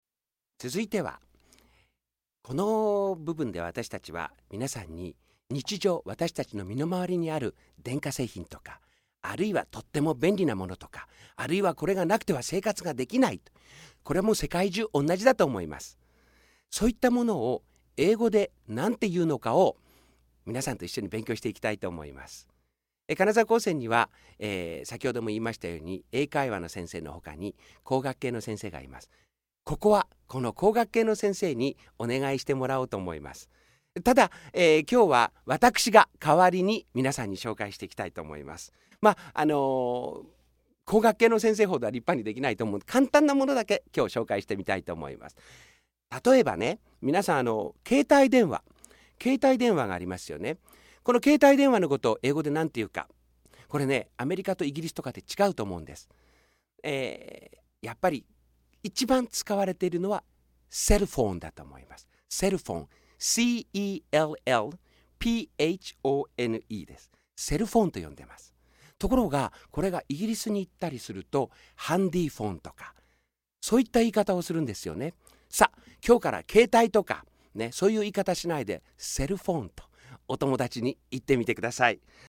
コーナー1　英会話レッスン